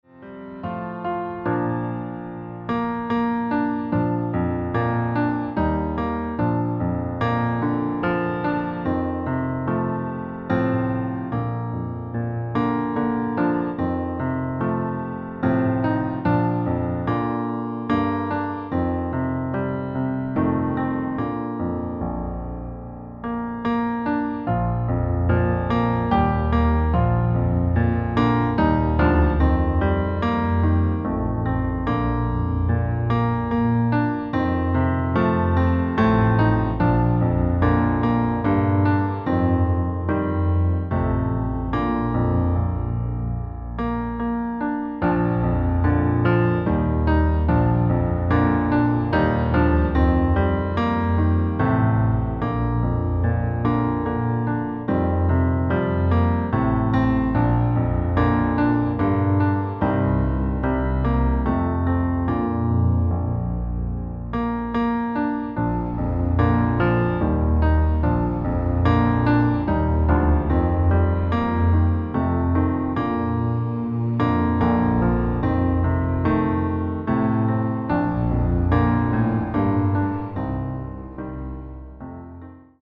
• Tonart: Bb Dur, C Dur , D Dur (Originaltonart )
• Art: Klavierversion mit Streichern
• Das Instrumental beinhaltet NICHT die Leadstimme